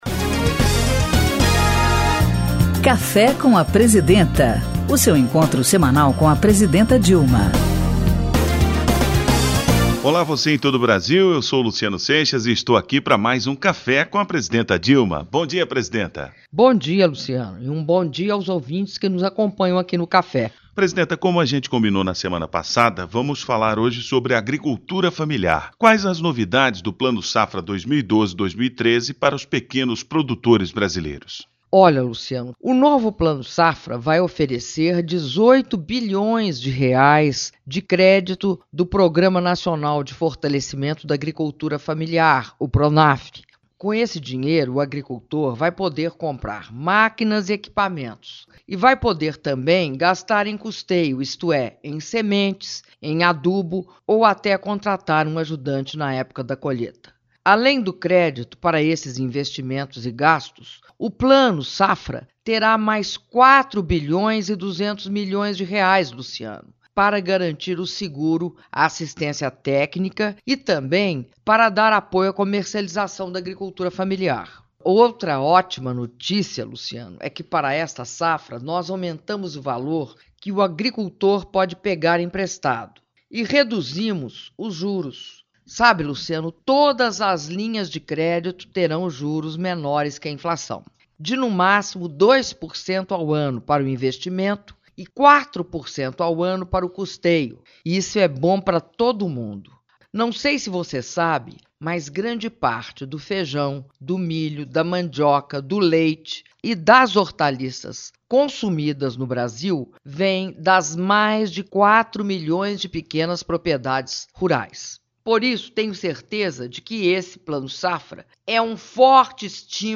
audio da entrevista concedida pela presidenta da republica dilma rousseff no programa de radio cafe com a presidenta 06min17s 1